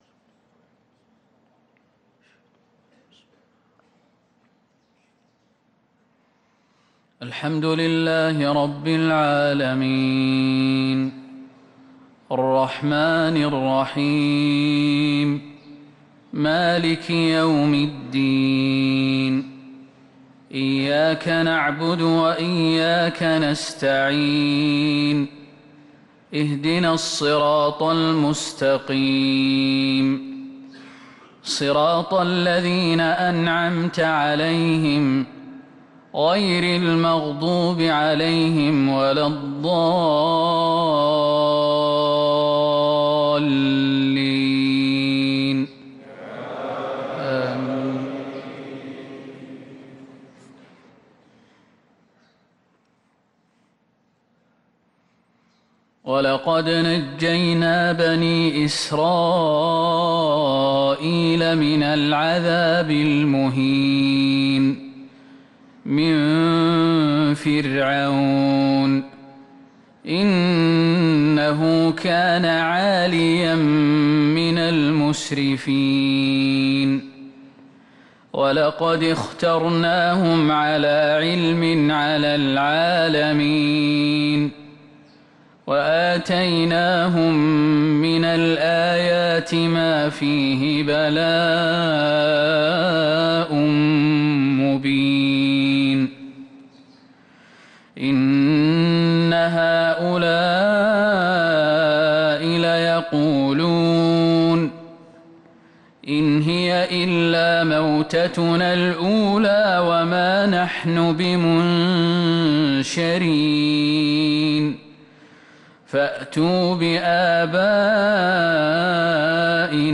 صلاة العشاء للقارئ عبدالرحمن السديس 24 صفر 1445 هـ